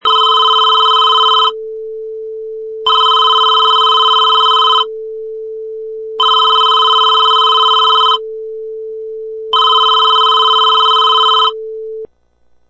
Sound effects: Telephone 4 Ring 7
Four successive rings on a telephone
Product Info: 48k 24bit Stereo
Relevant for: phone, telephones, ringing, cell, phone, ring, tone.
Try preview above (pink tone added for copyright).
Telephone_4_Ring_7.mp3